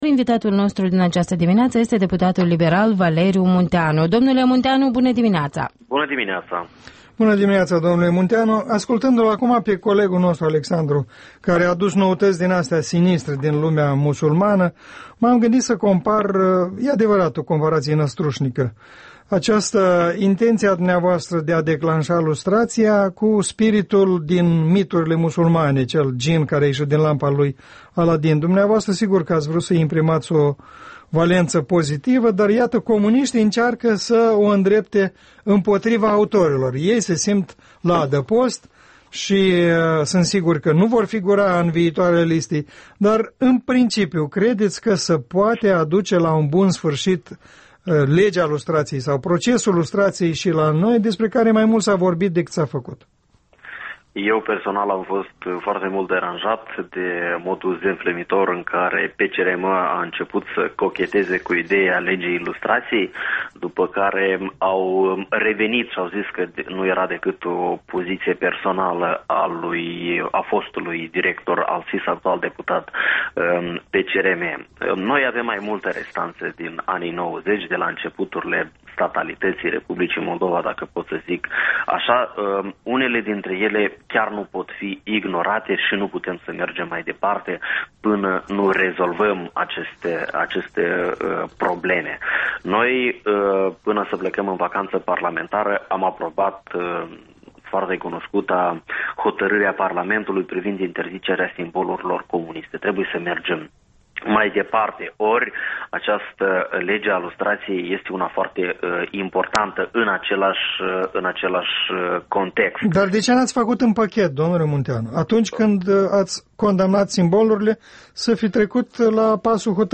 Interviul dimineții la REL: cu deputatul Valeriu Munteanu despre perspectiva lustrației în Moldova